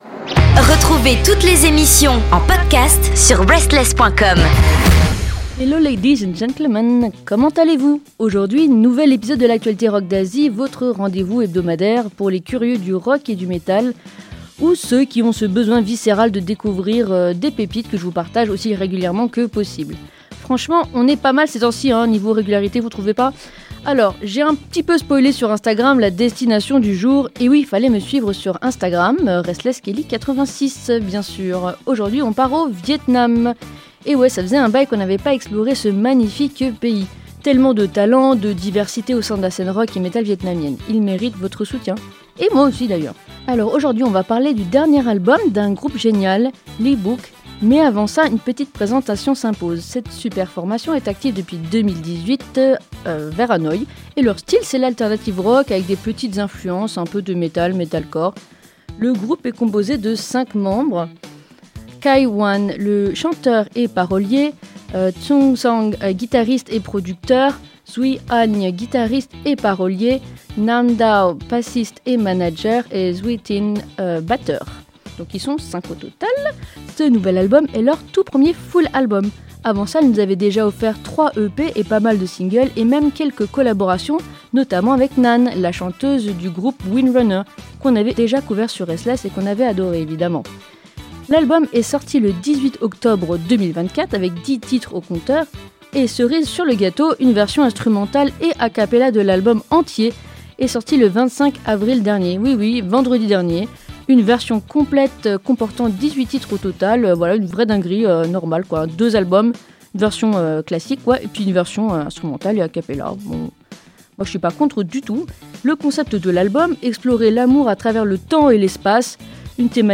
Cette semaine, direction Hanoï avec le groupe Lý Bực qui nous livre un morceau aux petits oignons ! Oui, c’est chanté presque entièrement en vietnamien (à 99% !) — mais franchement, c’est magnifique du début à la fin.